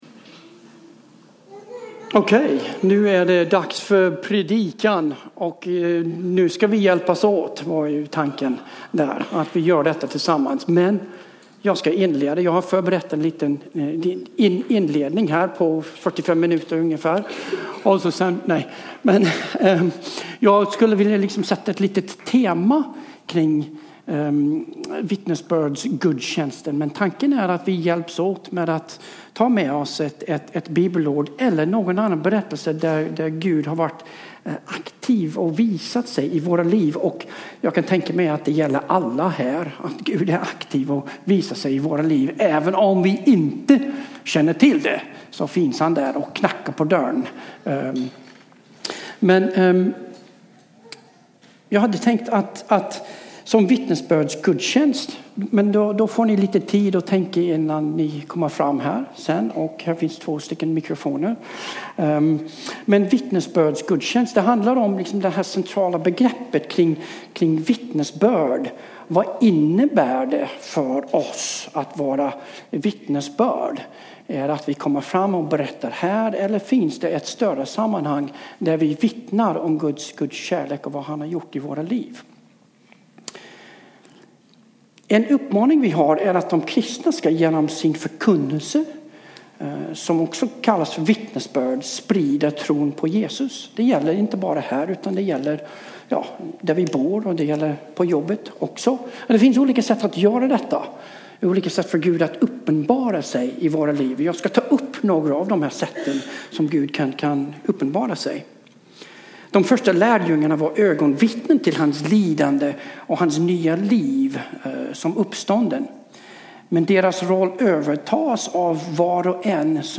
Predikan: “Överlåtelse